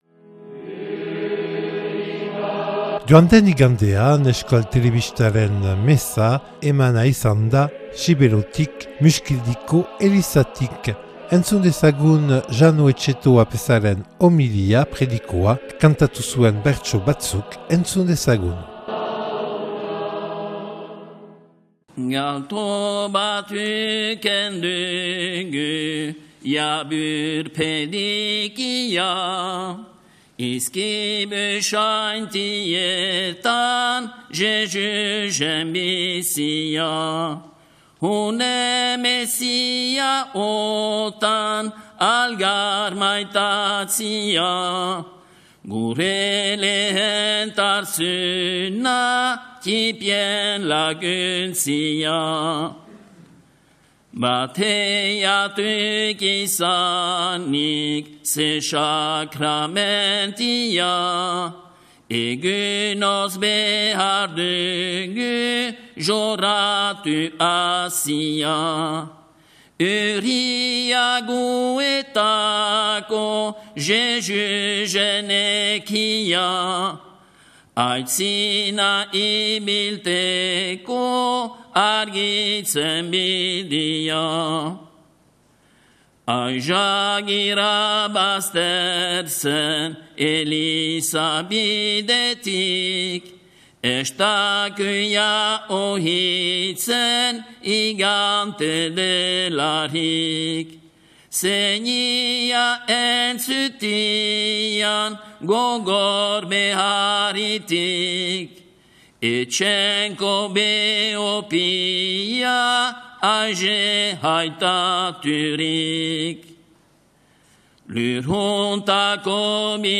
2022ko Urtarrilaren 30ean : mezaren zati batzuk.